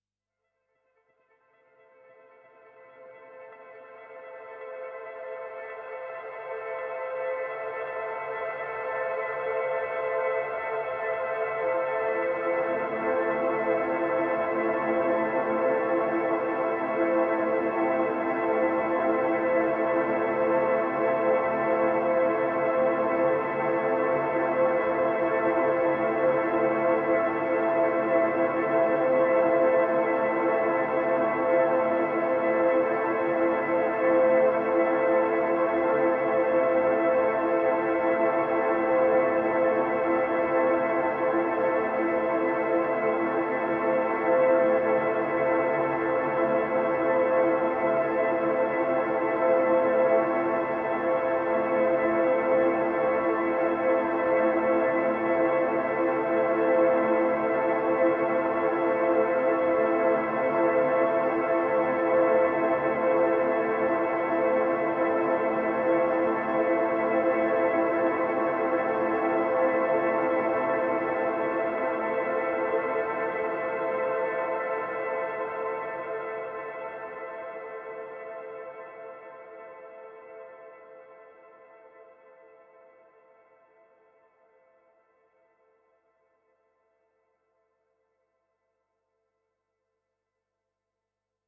Airy pads flutter to form a tense and restless atmosphere.